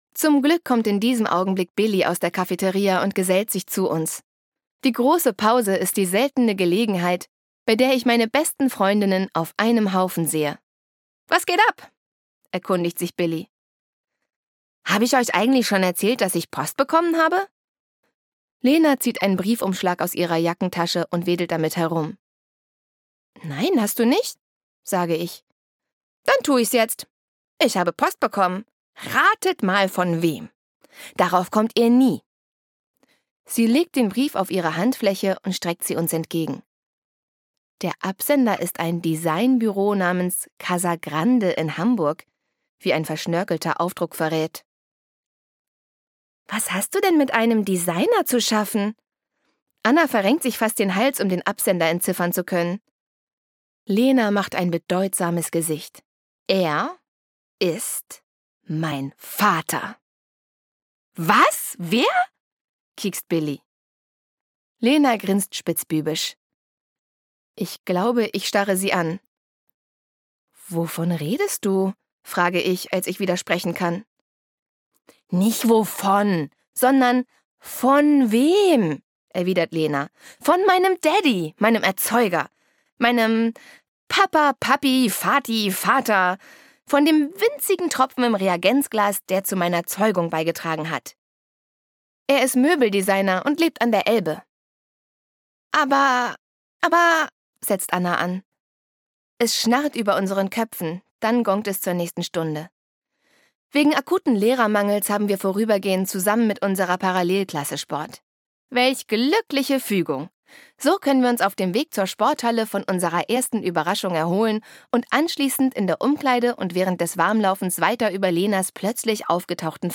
Conni 15 3: Meine beste Freundin, der Catwalk und ich - Dagmar Hoßfeld - Hörbuch